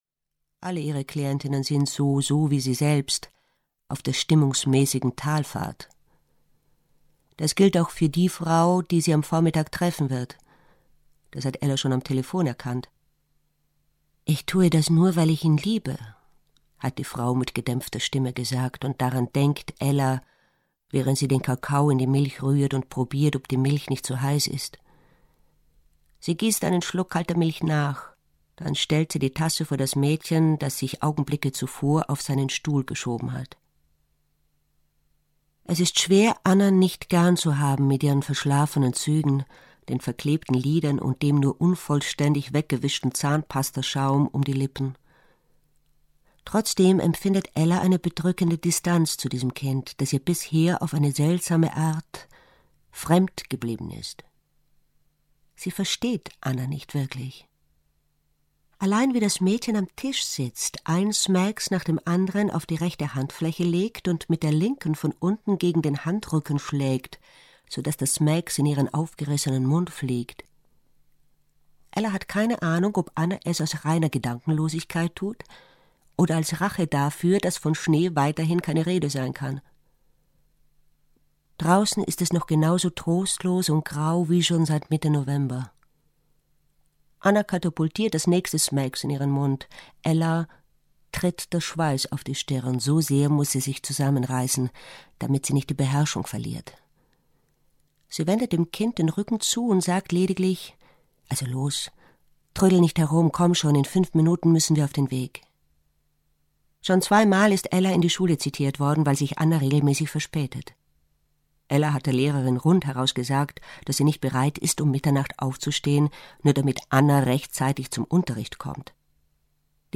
Anna nicht vergessen - Arno Geiger - Hörbuch